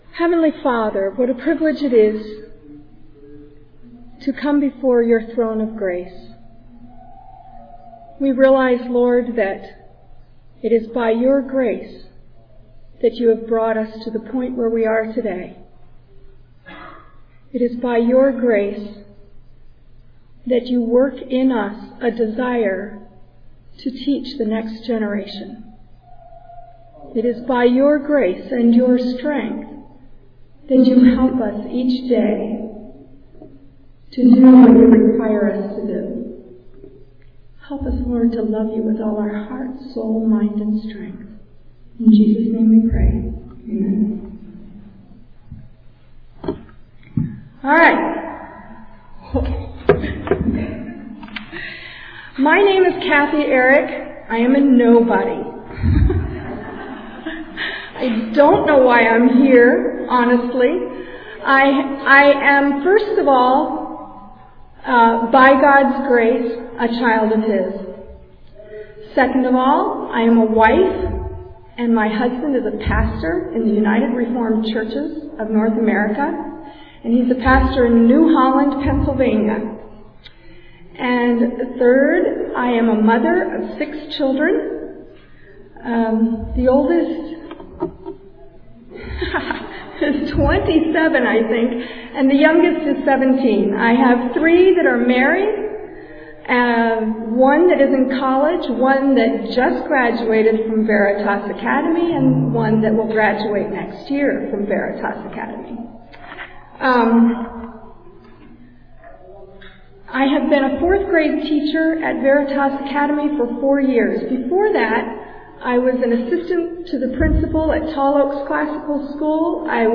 2007 Workshop Talk | 0:45:20 | K-6, Literature
Mar 11, 2019 | Conference Talks, K-6, Library, Literature, Media_Audio, Workshop Talk | 0 comments
The Association of Classical & Christian Schools presents Repairing the Ruins, the ACCS annual conference, copyright ACCS.